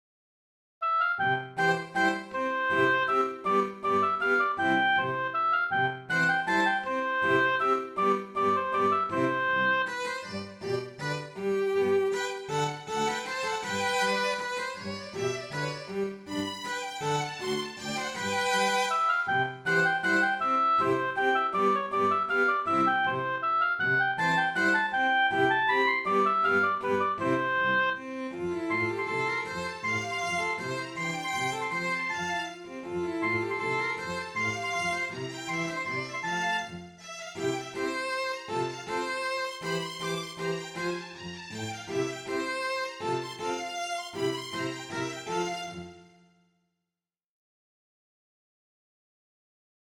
The first movement of a Quartettino for oboe and strings, attributed to Swabia’s
by the renowned MacFinale Ensemble playing period midi instruments!
Menuetto